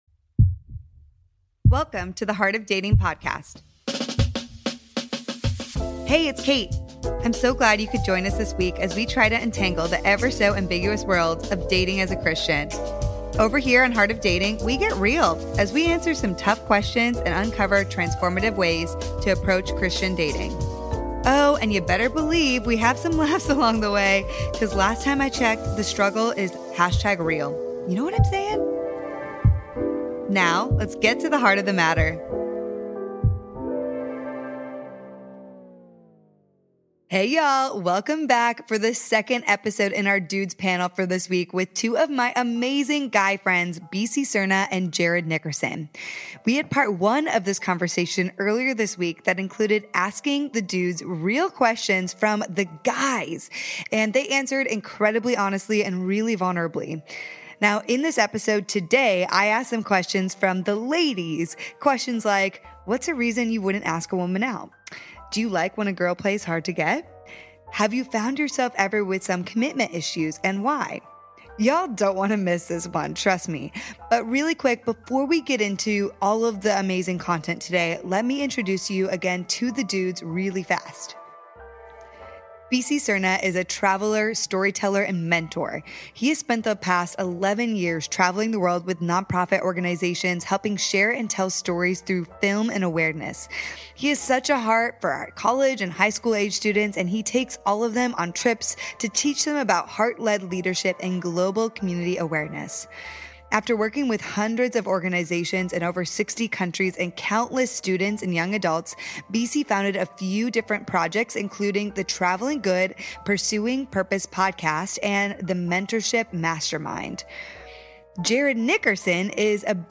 Single Men Panel: Dealbreakers, makeup, why they don't ask women out.